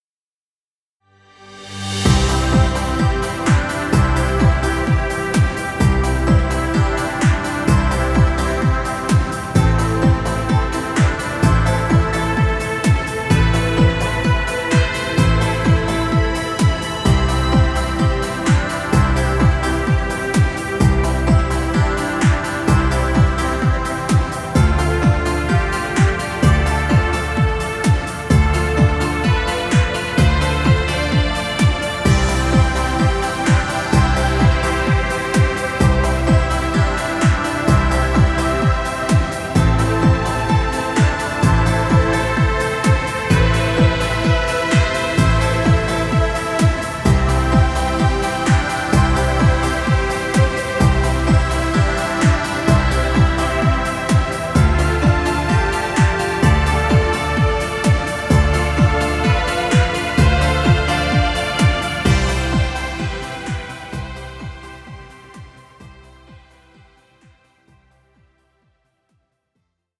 Dance music.